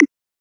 percs stuff » tom click
标签： minimal dance reverb tech progressive sound bip techno effect digital bleep fx sfx noise electro tom fugure trance click electronic glitch pop abstract house
声道立体声